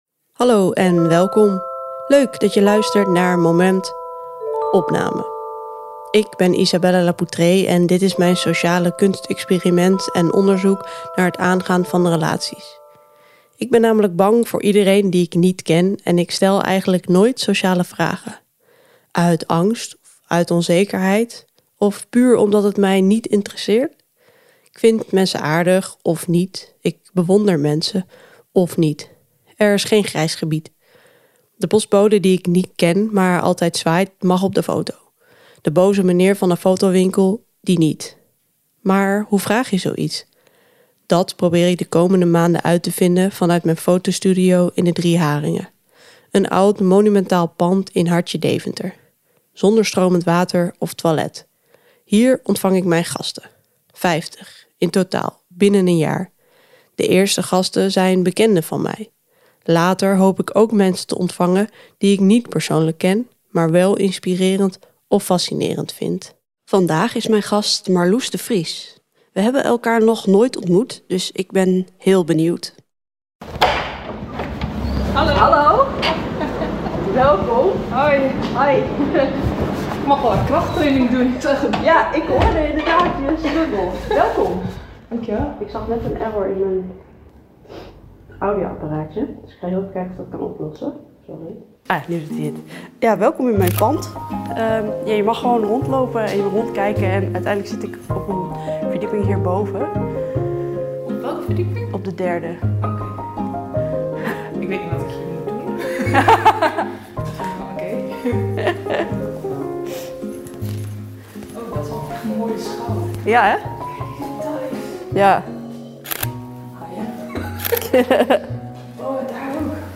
Daarna kruipen ze achter de microfoon. In eerlijke, open en grappige gesprekken praat ze met hen over het leven, ongemak, dromen en do